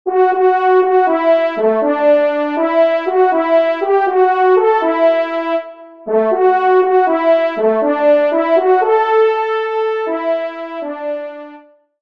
Trompe (Solo, Ton simple)